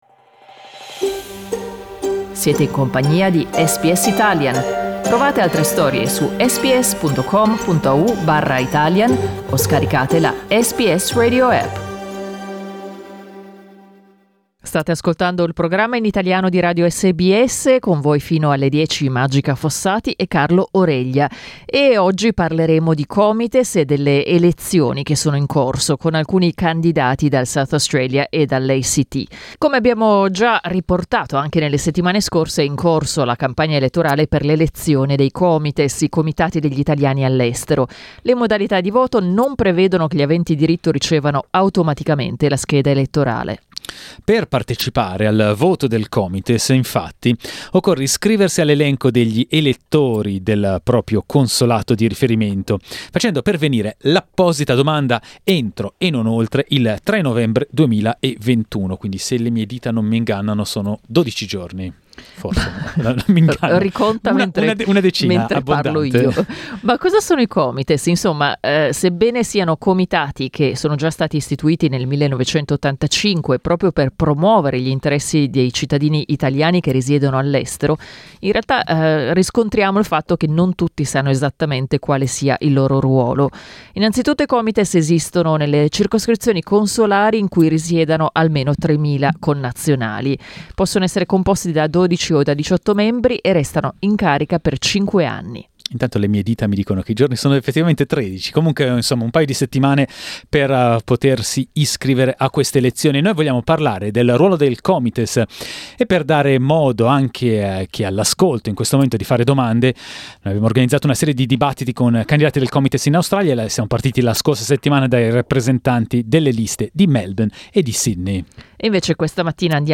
In occasione della campagna elettorale per l'elezione dei COMITES, Comitati degli Italiani all’estero, ospitiamo una serie di dibattiti con alcuni candidati australiani.